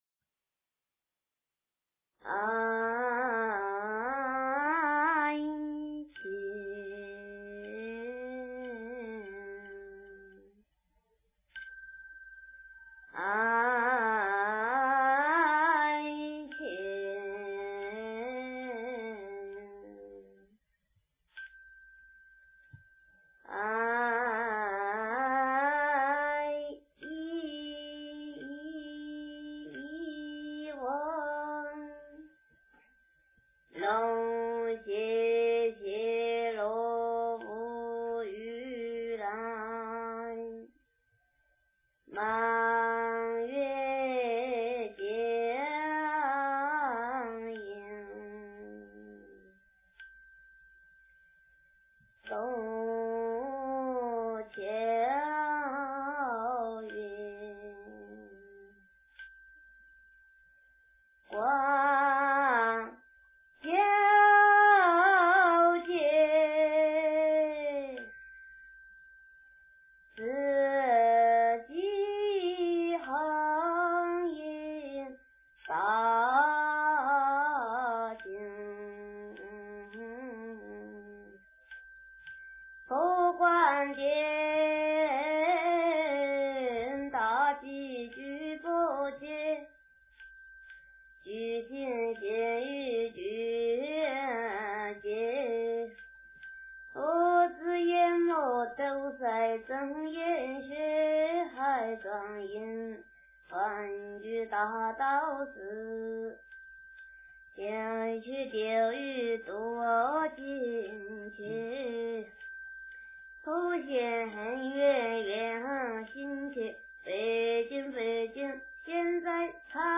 华严总赞--僧团 经忏 华严总赞--僧团 点我： 标签: 佛音 经忏 佛教音乐 返回列表 上一篇： 般若菠萝蜜颂--佚名 下一篇： 般若波罗蜜多心经--诵念快版 相关文章 药师赞--圆光佛学院众法师 药师赞--圆光佛学院众法师...